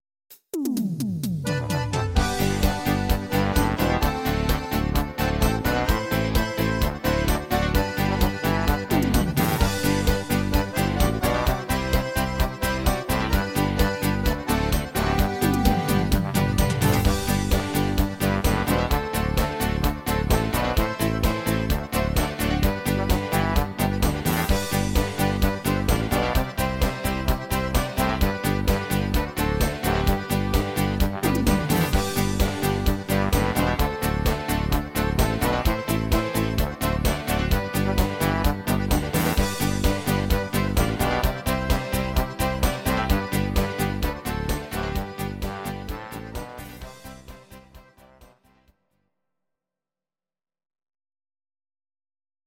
These are MP3 versions of our MIDI file catalogue.
Please note: no vocals and no karaoke included.
Your-Mix: Volkstï¿½mlich (1262)